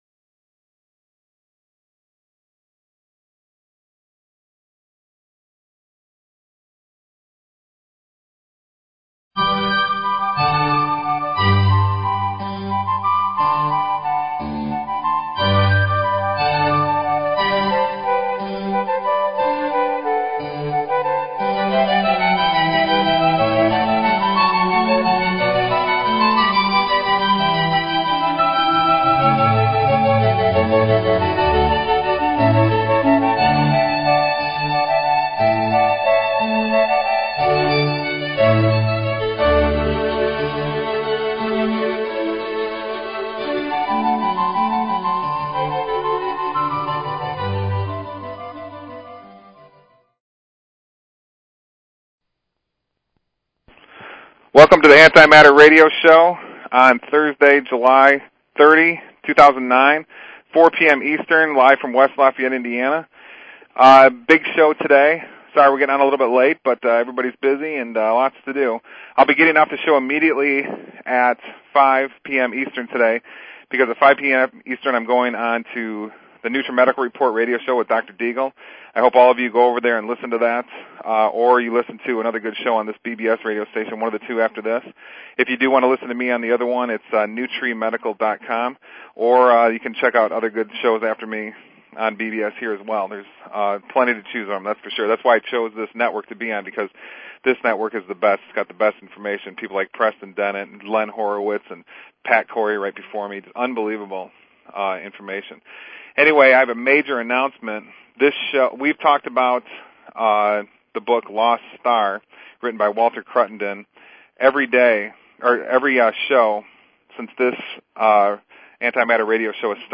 Talk Show Episode, Audio Podcast, The_Antimatter_Radio_Show and Courtesy of BBS Radio on , show guests , about , categorized as